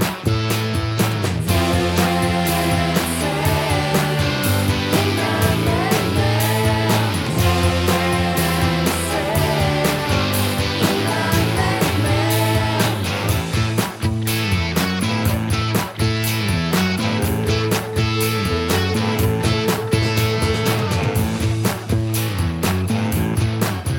Minus High Lead Guitar Glam Rock 3:31 Buy £1.50